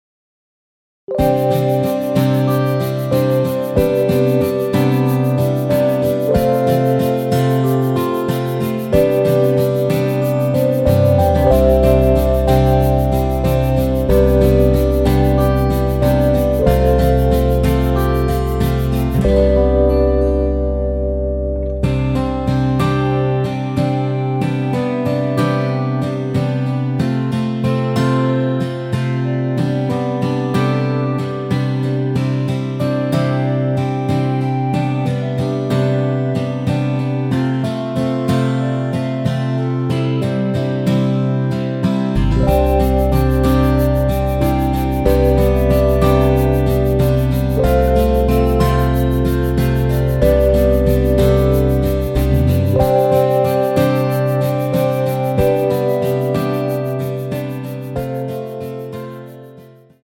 원키에서(-1)내린 멜로디 포함된 MR입니다.
◈ 곡명 옆 (-1)은 반음 내림, (+1)은 반음 올림 입니다.
앞부분30초, 뒷부분30초씩 편집해서 올려 드리고 있습니다.